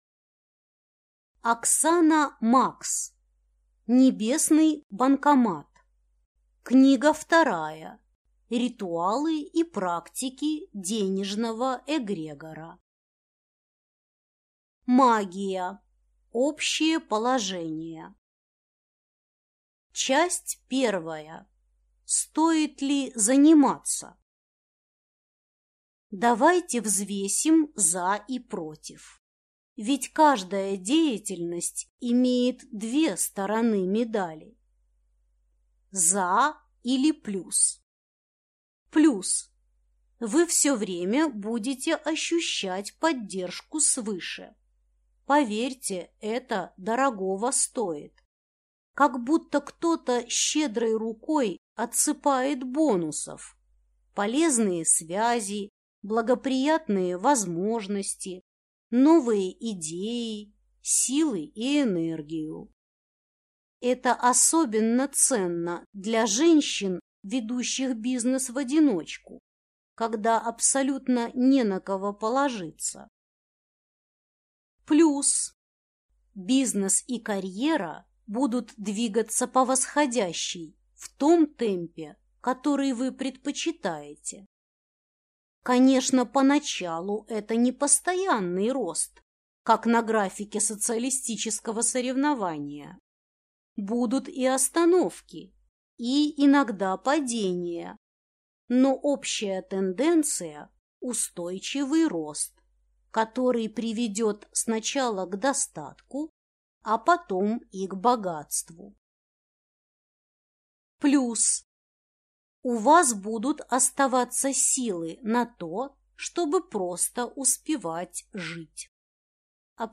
Аудиокнига Небесный банкомат. Книга 2. Ритуалы и практики денежного эгрегора | Библиотека аудиокниг